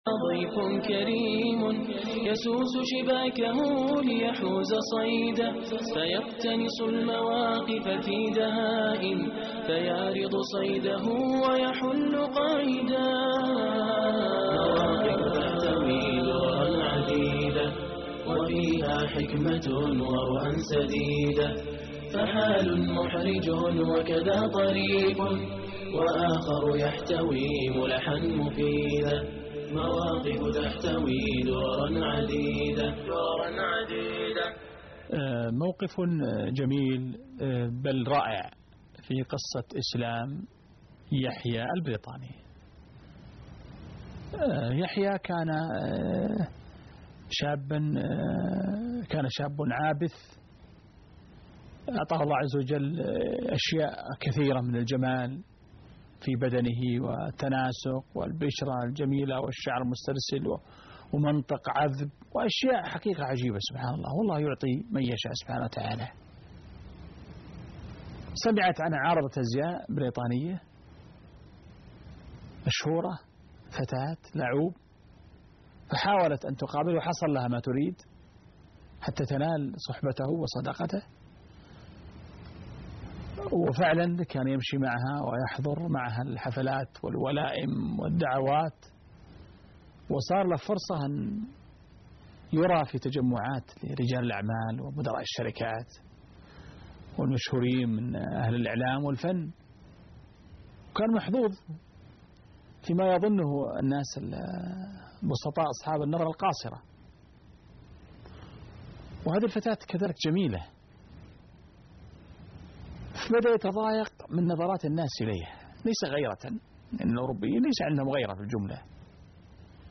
عنوان المادة حوار مع مستهزئ